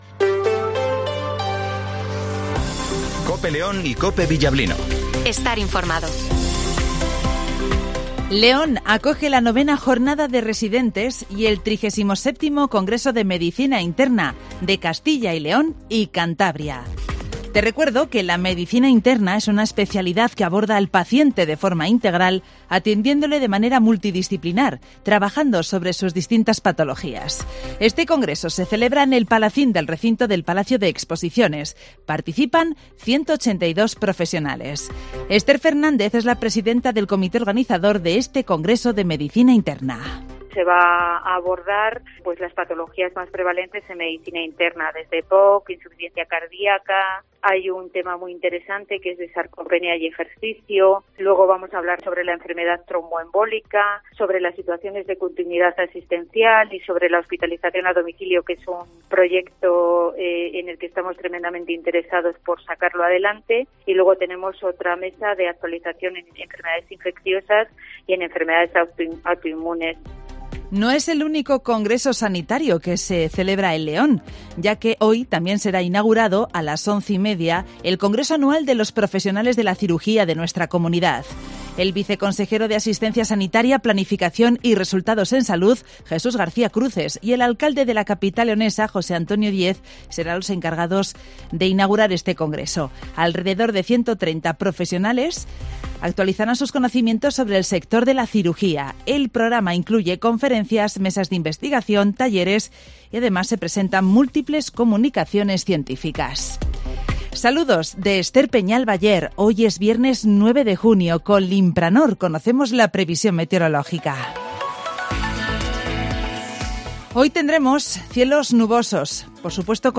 Redacción digital Madrid - Publicado el 09 jun 2023, 08:25 - Actualizado 09 jun 2023, 11:43 1 min lectura Descargar Facebook Twitter Whatsapp Telegram Enviar por email Copiar enlace - Informativo Matinal de las 08:20 h